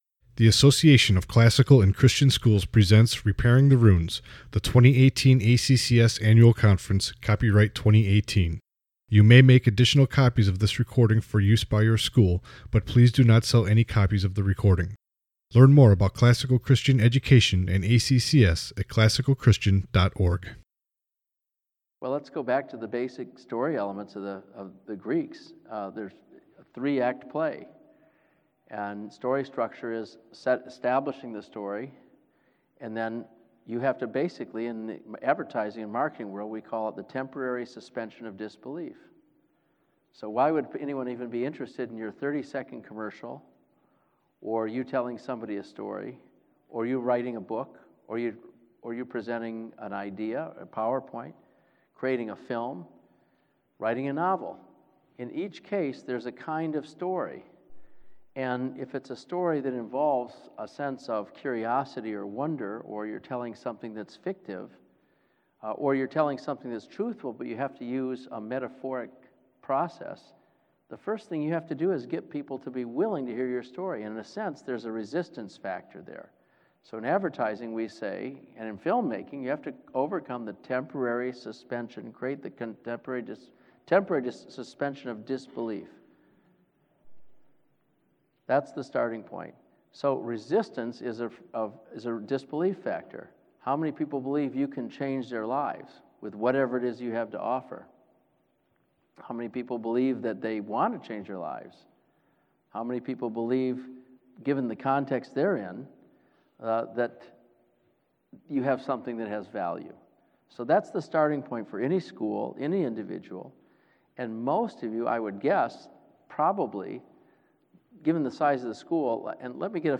2018 Leaders Day Talk | 46:60 | Marketing & Growth